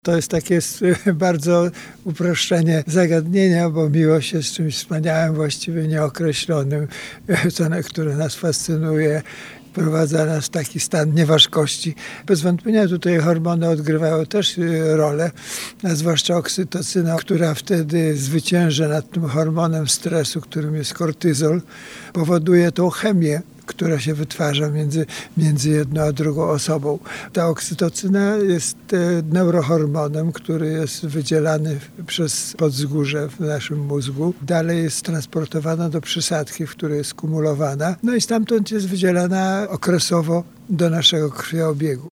Cała rozmowa w piątek 14 lutego po godz. 8:10 w audycji „Poranny Gość”.